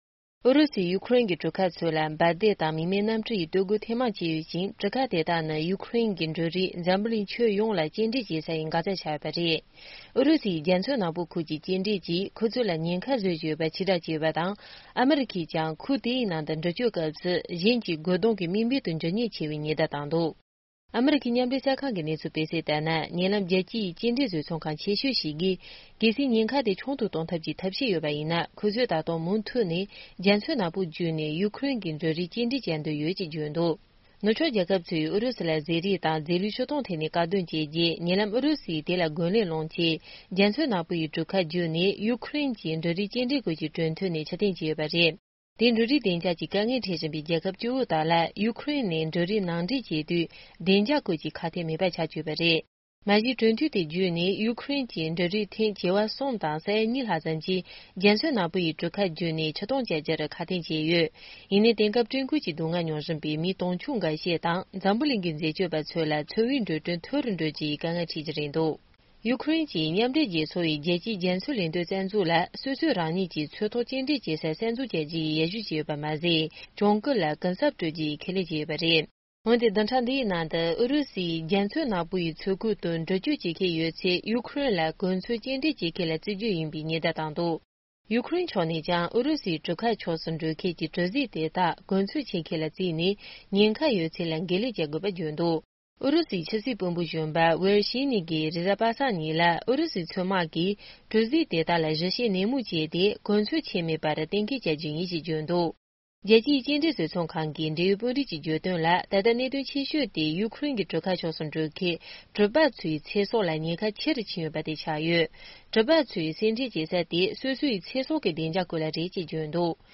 སྙན་སྒྲོན་ཞུས་ཡོད།